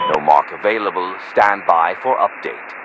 Radio-jtacSmokeNoTarget4.ogg